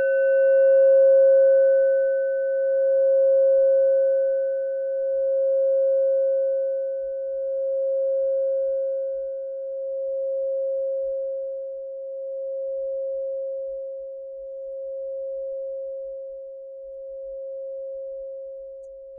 Klangschale Nepal Nr.26
Klangschale-Gewicht: 910g
Klangschale-Durchmesser: 14,2cm
(Ermittelt mit dem Filzklöppel)
klangschale-nepal-26.wav